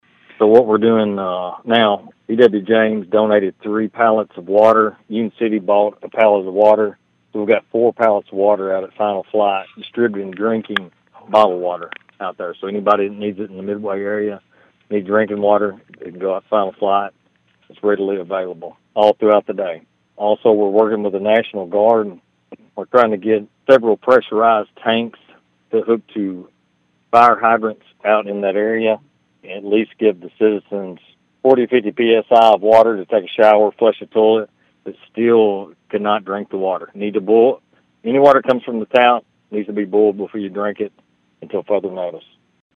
McTurner said drinking water is being provided to the residents, with attempts also ongoing to receive some assistance from the National Guard.(AUDIO)